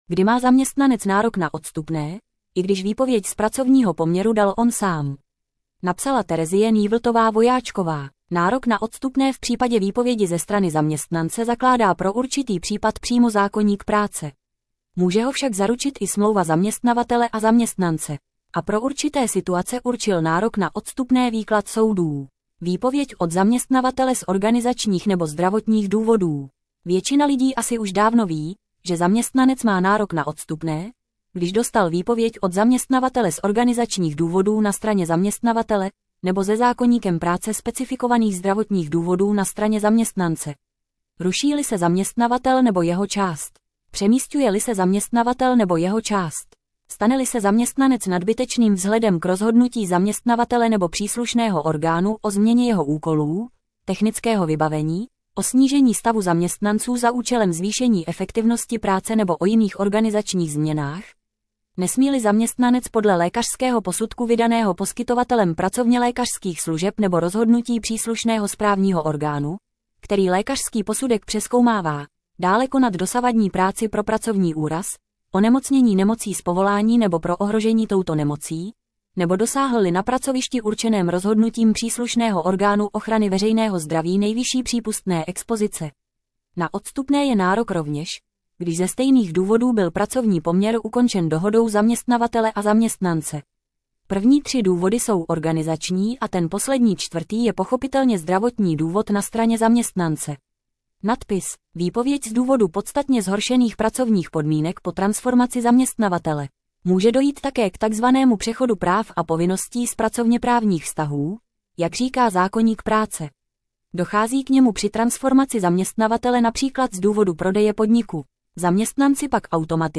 Tento článek pro vás načetl robotický hlas.